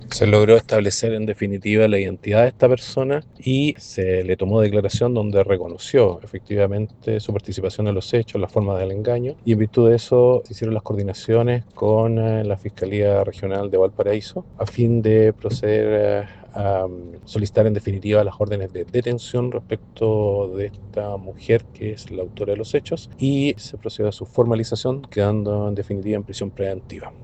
El fiscal Guerrero indicó que la imputada reconoció el engaño al enfrentar a la justicia en la región de Valparaíso, donde quedó con la medida cautelar de prisión preventiva.